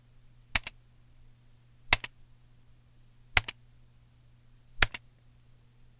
描述：点击计算机键盘上的键
Tag: 按键 MTC500-M002-S14 计算机